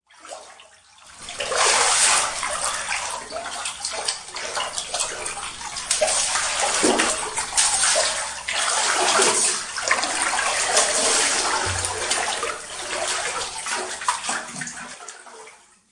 冰层破损 湿润飞溅的ST
描述：在池塘表面形成薄薄的冰盖